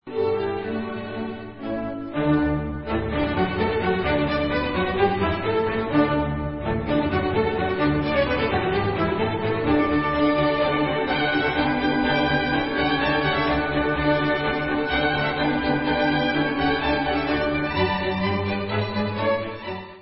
housle
Koncert pro housle a orchestr č. 1 D dur, op. 3:
Allegro sostenuto